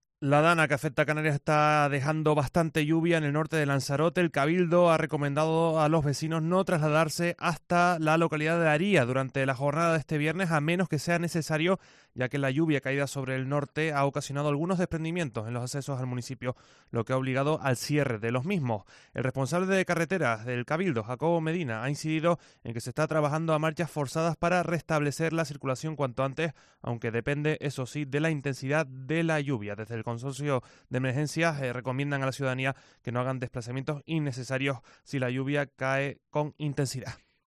Lluvia, escorrentías y barro en Haría por la DANA